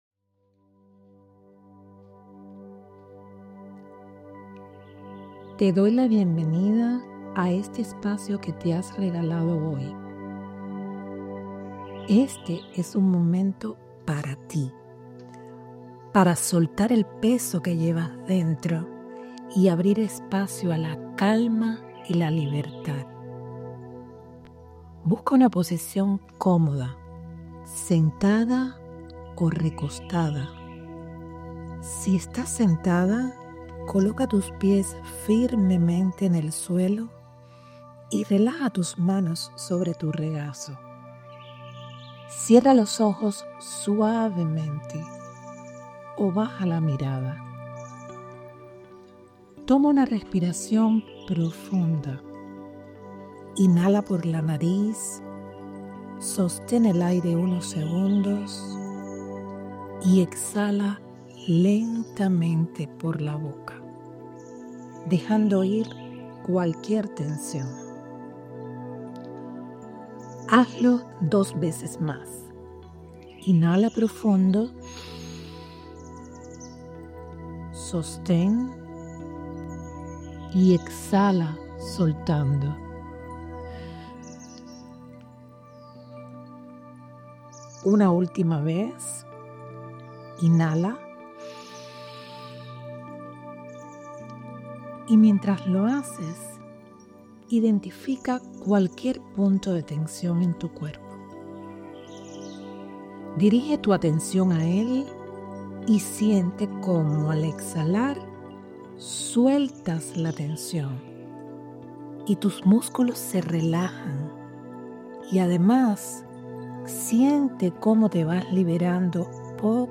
BAJAR LA MEDITACIÓN